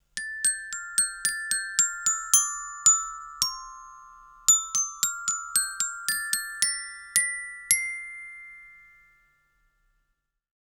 Melodía sencilla interpretada con un carillón
idiófono
percusión
campanilla
carillón